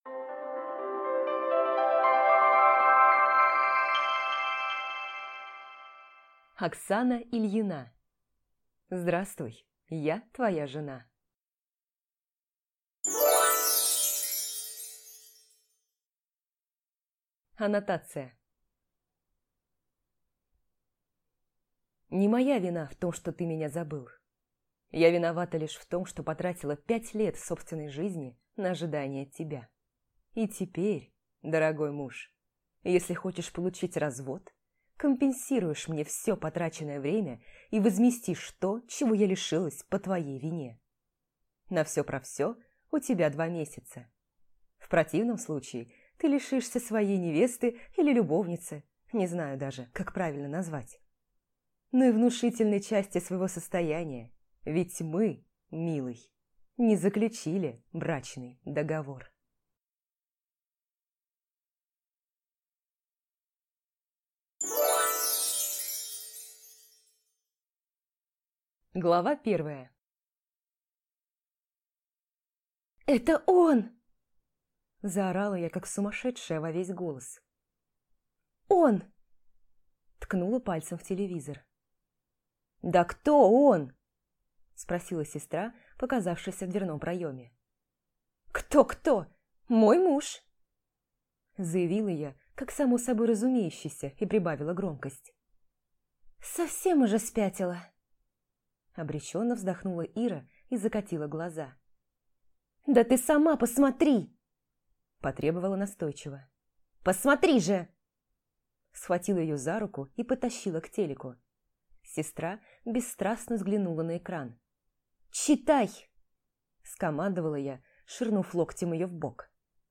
Аудиокнига Здравствуй, я твоя жена | Библиотека аудиокниг